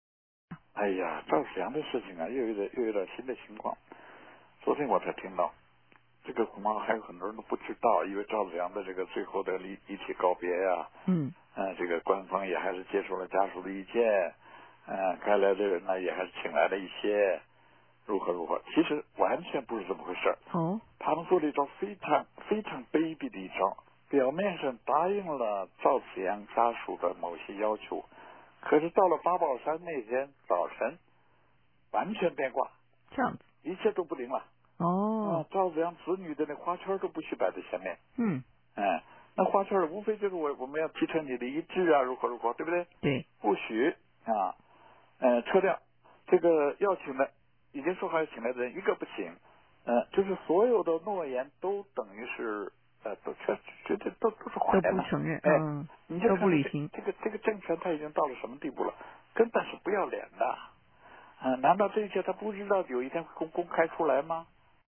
"The masses will soon rise, and people from different regions will join hands in their struggle," former People's Daily journalist Liu Binyan told RFA in a recent interview to mark his 80th birthday.